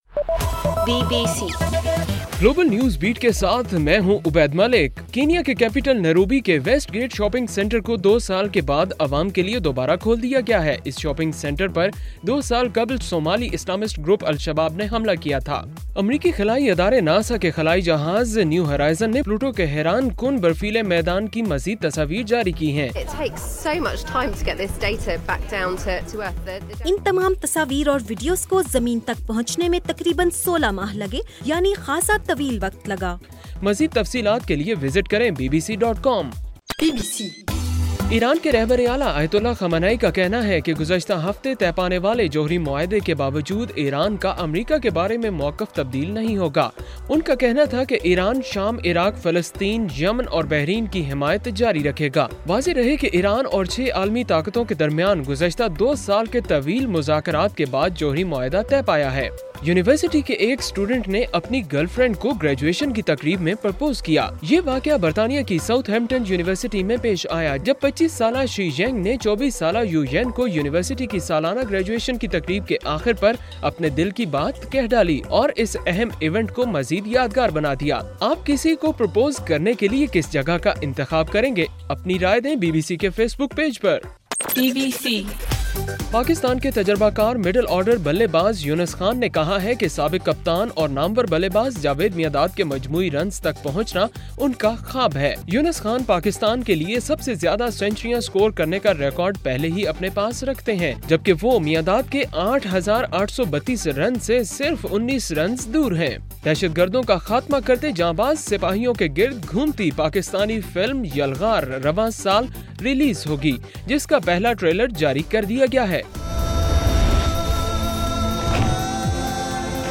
جولائی 18: رات 8 بجے کا گلوبل نیوز بیٹ بُلیٹن